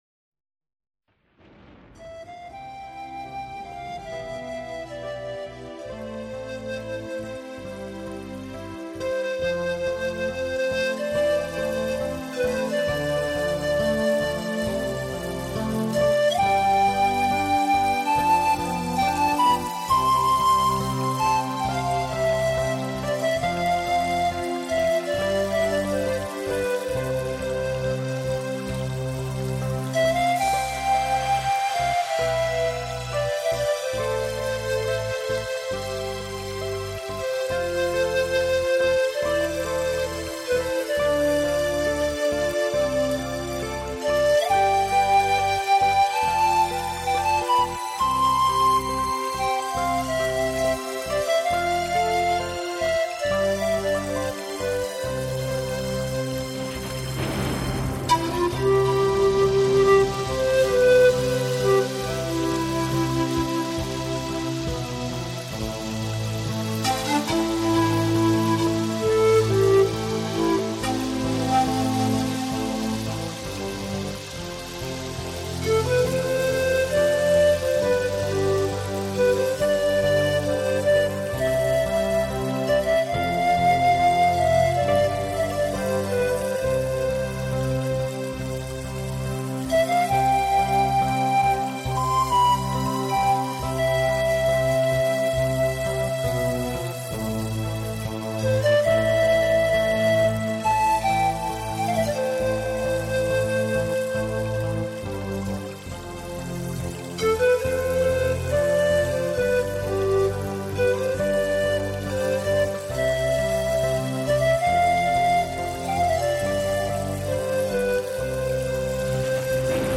印第安音乐中咚咚的鼓声就如同各种生物的心跳声一样。